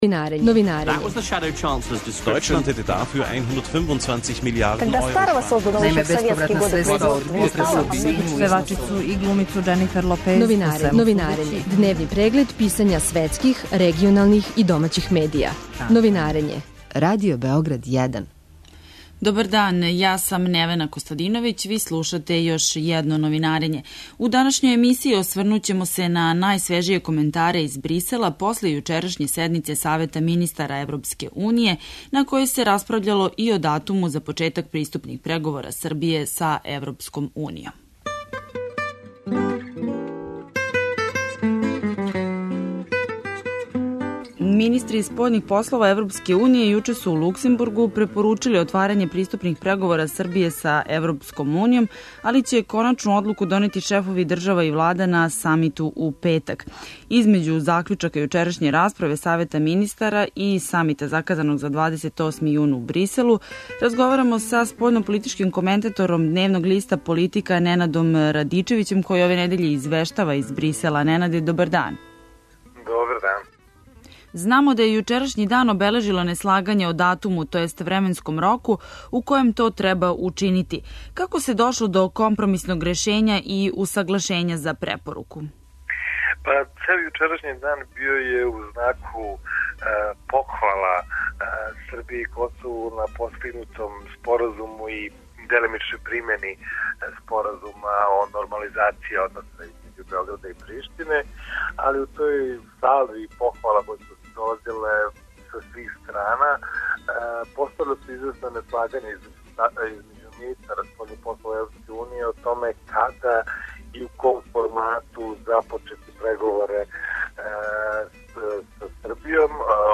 разговарамо са спољнополитичким коментатором